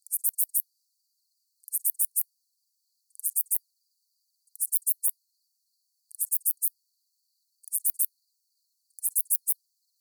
This Page is Under Construction armored shieldback Aglaothorax armiger Rehn and Hebard 1920 map 10 s of calling song and waveform. Clark County, Nevada; 18.1°C. JCR230609_004.